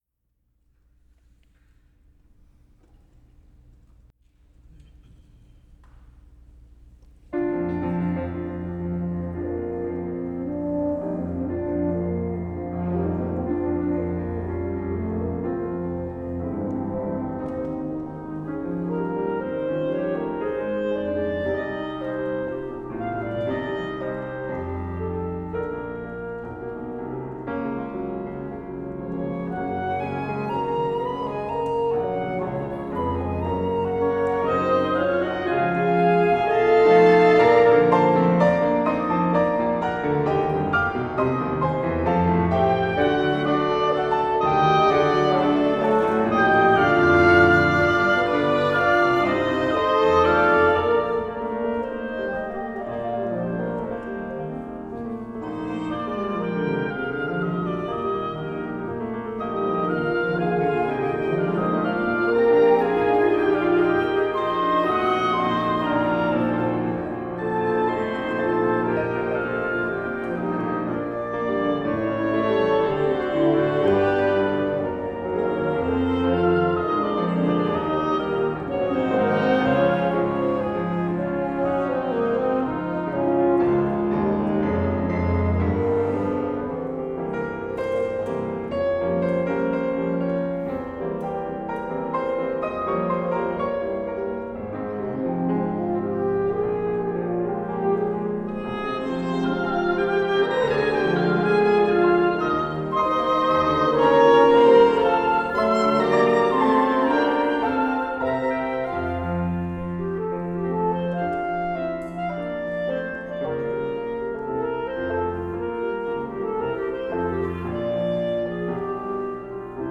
Horn
Bassoon
Flute
Oboe
Piano
Clarinet